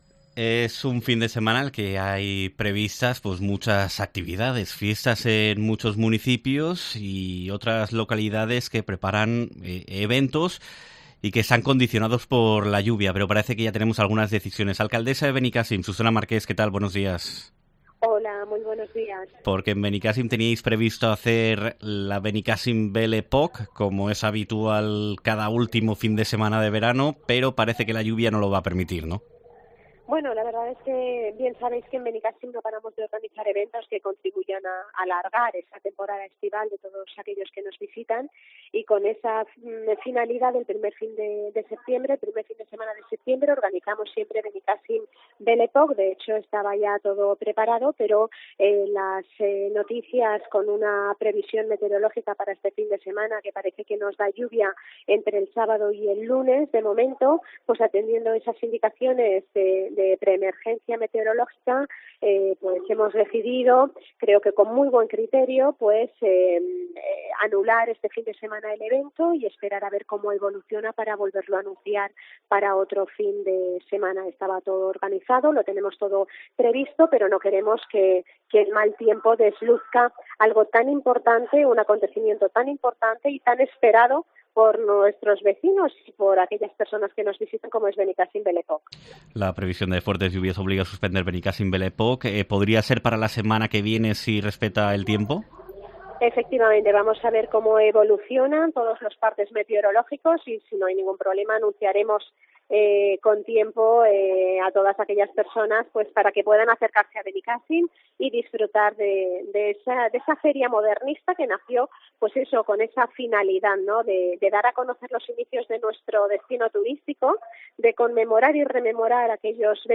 Entrevista
La Benicàssim Belle Époque se retrasa una semana ante la previsión de fuertes lluvias, tal y como en COPE explica la alcaldesa, Susana Marqués